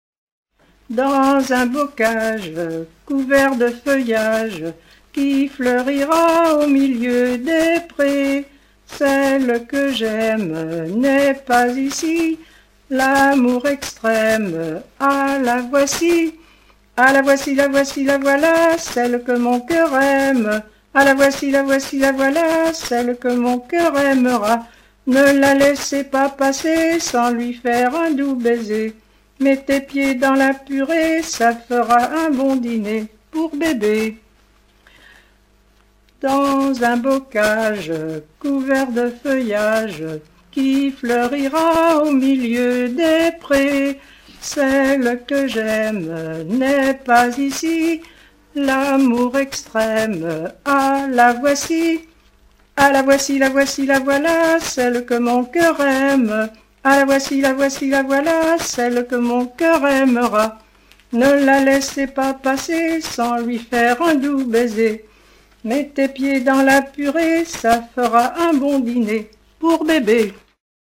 La voici la voilà - 007916 Thème : 0079 - L'enfance - Rondes enfantines à baisers ou mariages Résumé : Au vert bocage, mon doux feuillage viens reverdir au milieu de nous.
ronde à embrasser